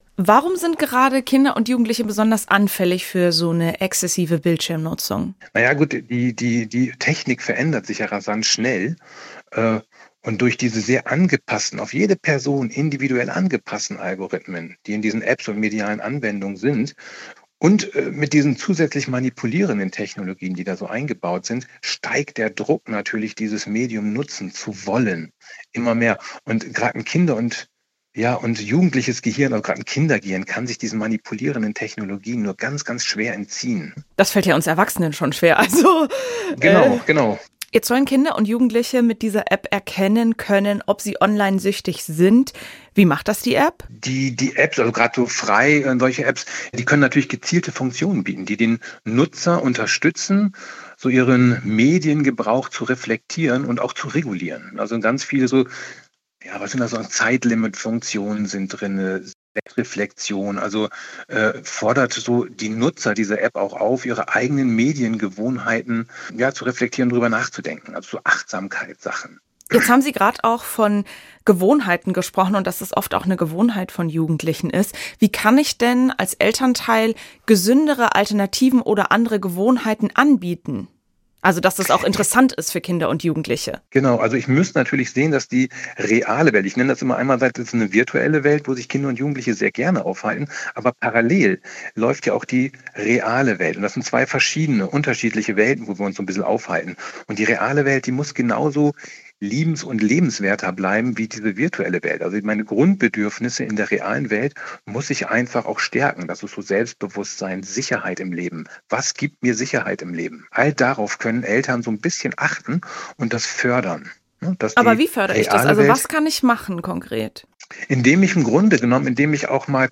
Das Interview führte